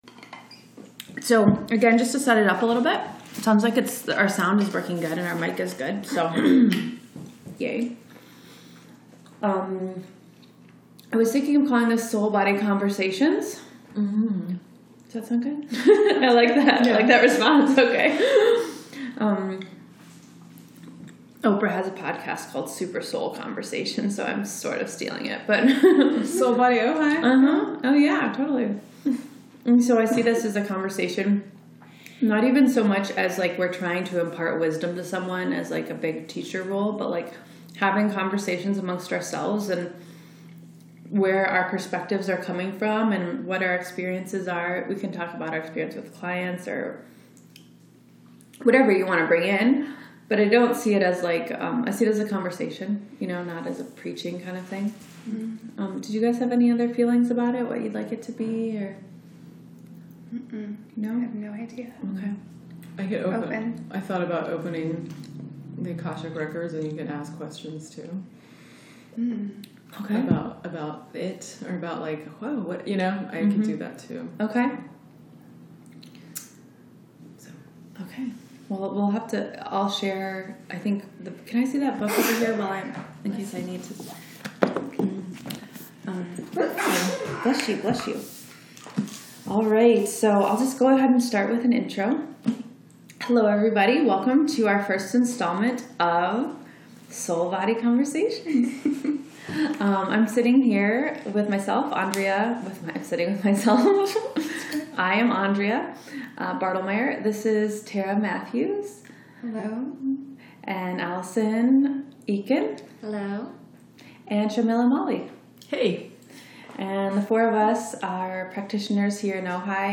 This discussion is about DNA, Akashic records, the soul purpose and the soul blue print and how it connects with our physical body via our DNA. This conversation is informal, interesting, entertaining and about 50 minutes long.